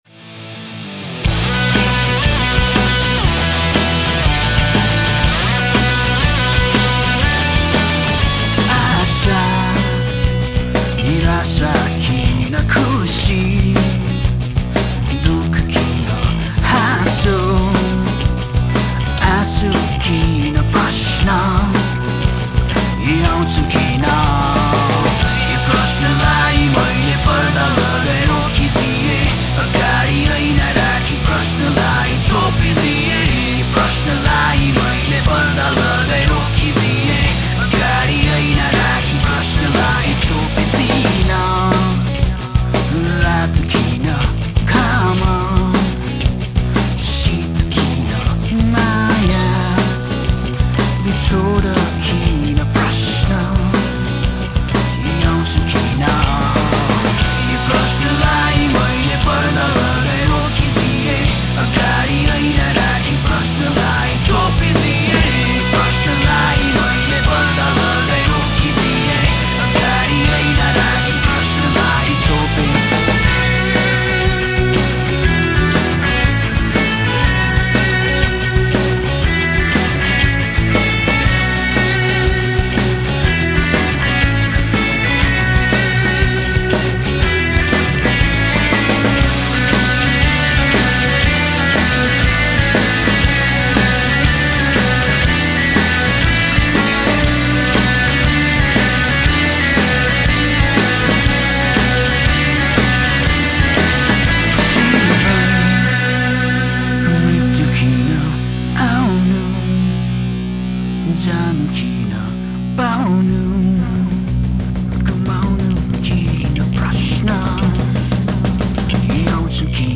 Alternative Hard Rock Song
Hey there, This is my first ever attempt at mixing a song.
It's an alternative hard rock type of song with lyrics/vocals in Nepali(I'm from Nepal, by the way).